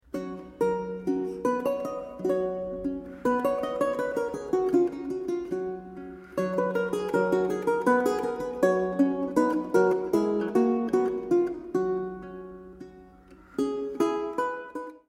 Renaissance and Baroque Guitar
Evropská kytarová hudba z 16. a 17. století
Kaple Pozdvižení svatého Kříže, Nižbor 2014